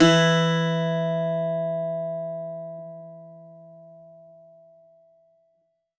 52-str02-bouz-e2.wav